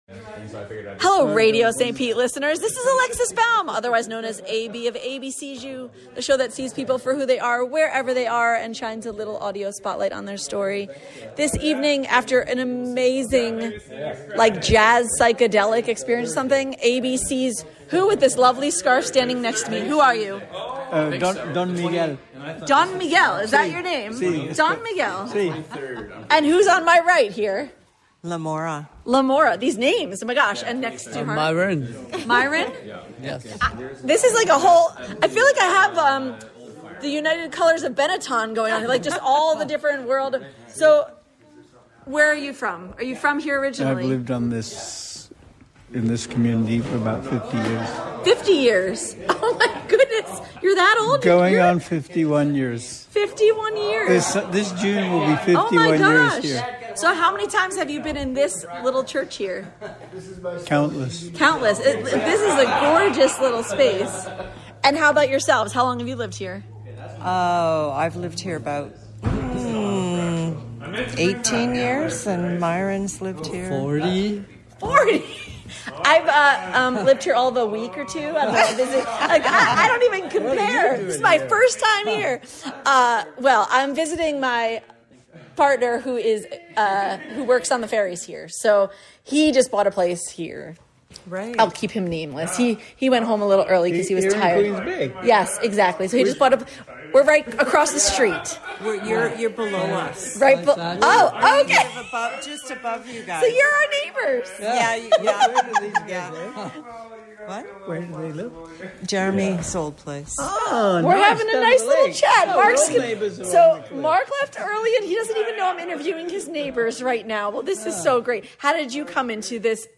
Enjoy the tune at the end.